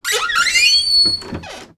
door.wav